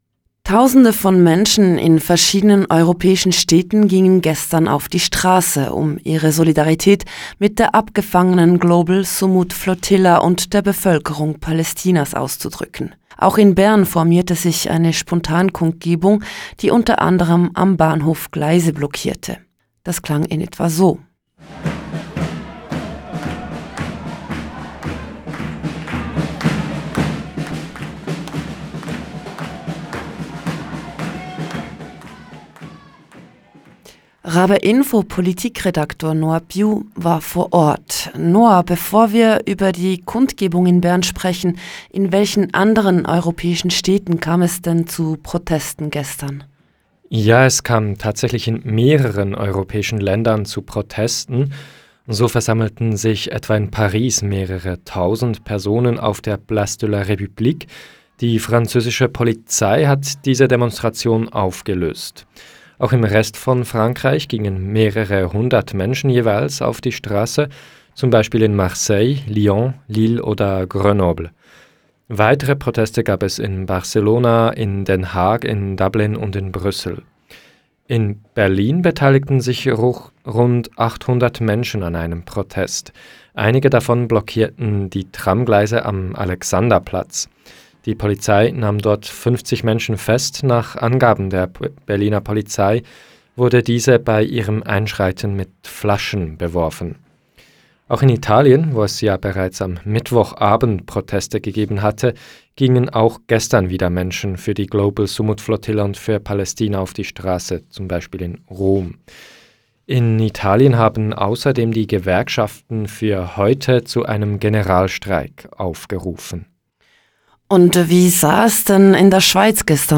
03.-Modigespraech-Demo.mp3.mp3